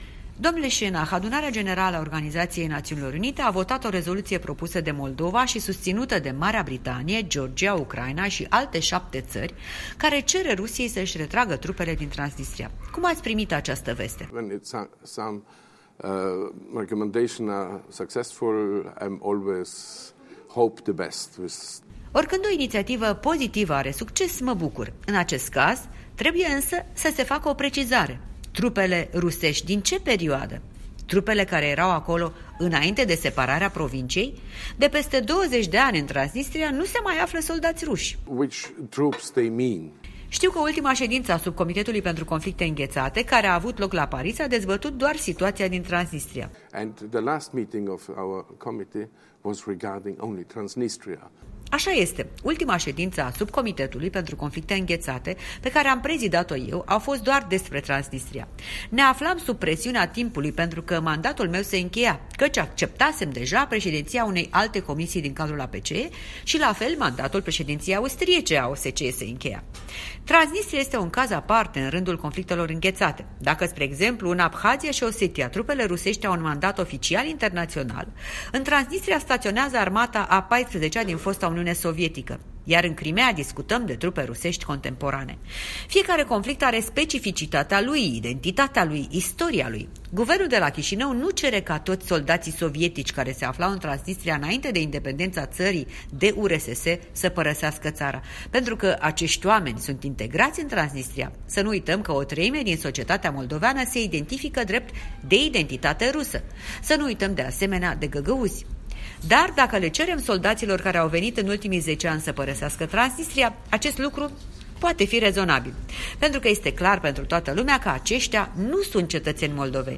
Un interviu cu fostul președinte al Comitetului pentru conflicte înghețate din cadrul APCE.
Interviu cu parlamentarul austriac Stefan Schennach